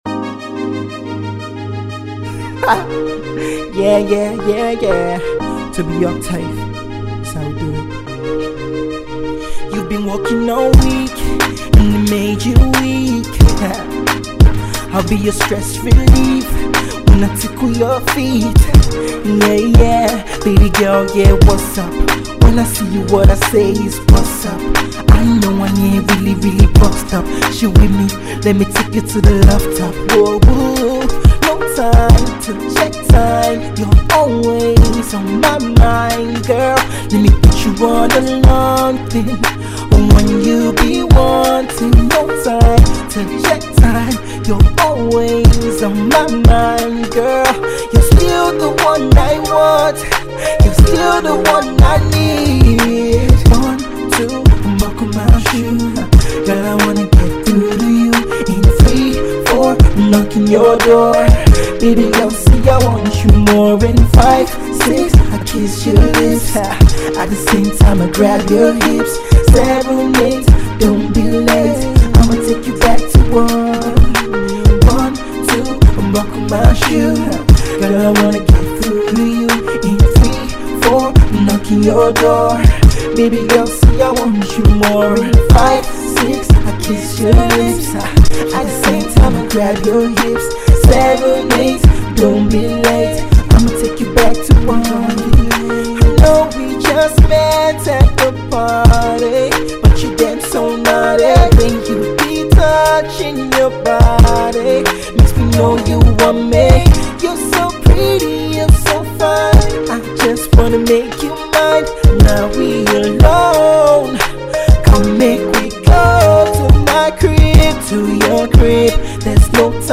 playful sexy freestyle
young Pop/RnB crooner